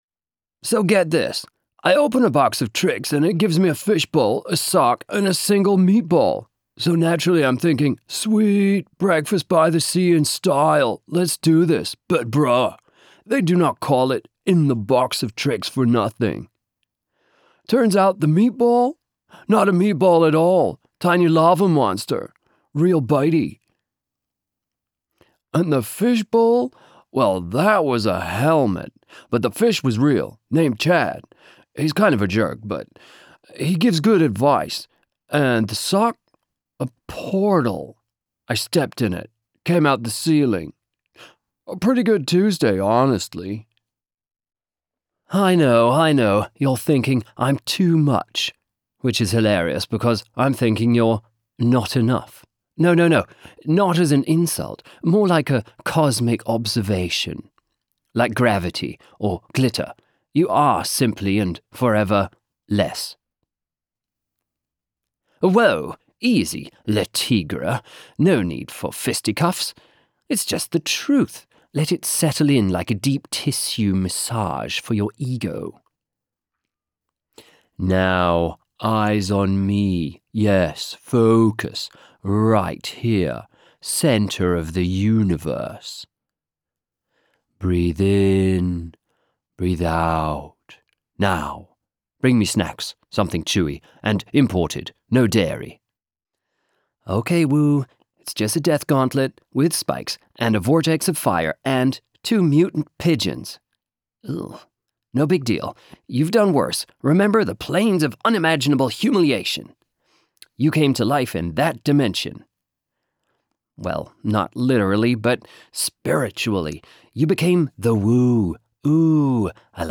Hi, if you need a soothing calm or fun voice, lets go
3 animated charachters! (surfer, mind reader and ninja)
Middle Aged
I have a professional studio setup with a sound booth.
Short animated series (laze, trippy, woo) polished.wav